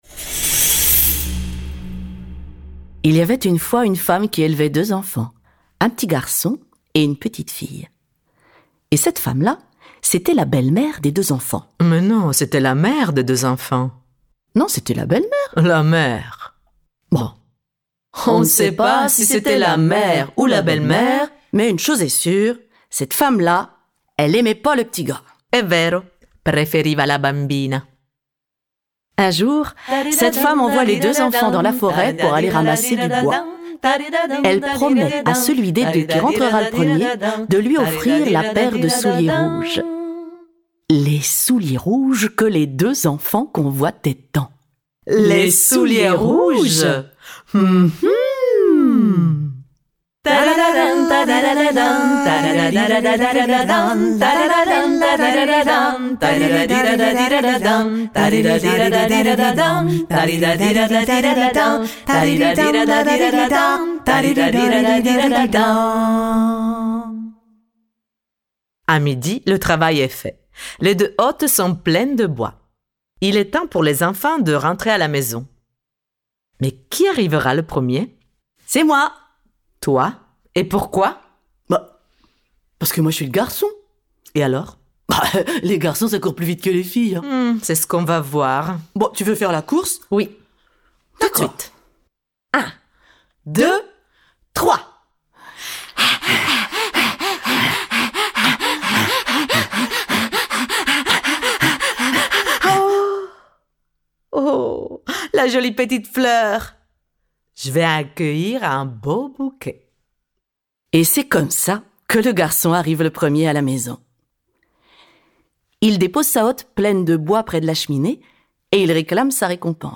Diffusion distribution ebook et livre audio - Catalogue livres numériques
Elles nous dénichent des versions de chez elles et les pétrissent avec des chants traditionnels aux saveurs d’huile d’olive et de beurre salé pour vous servir un plat de rires, de folies et d’exubérance.